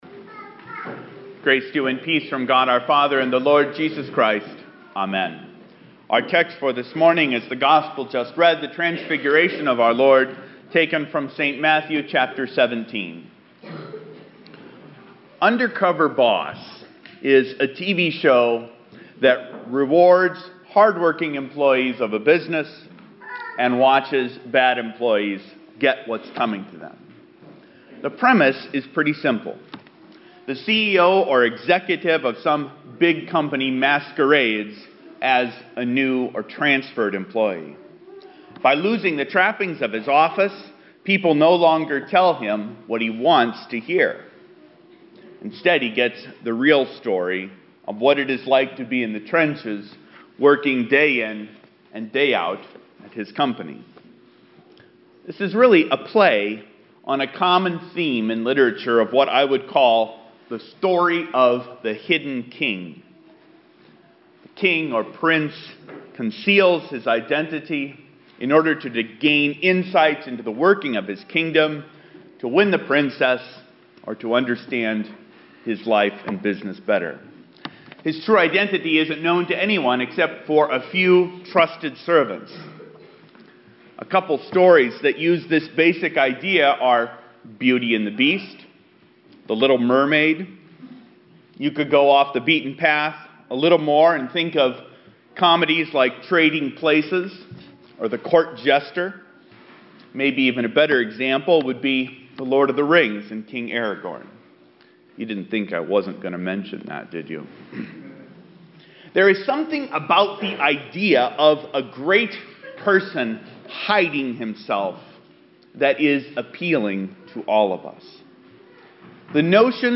[This sermon is slightly more inclusive of pop culture references than usual for me.